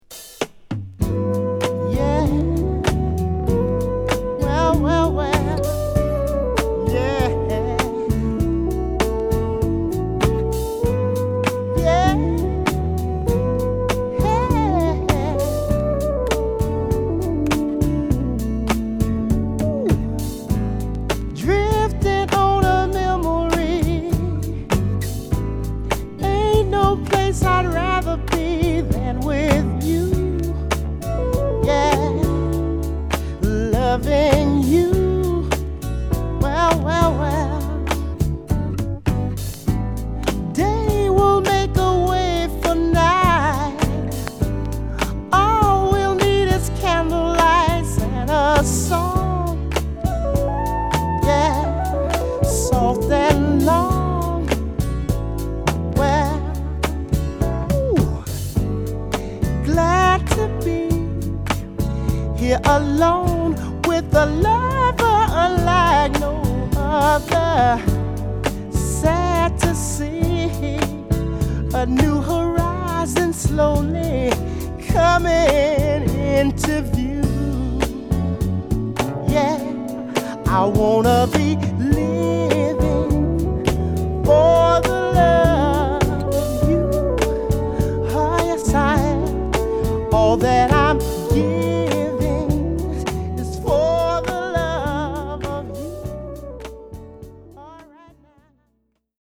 メロウなギターや独特のメロディが堪らないシンセ等が入り乱れるトラックに優しすぎるヴォーカルがサイコーです！
(Stereo)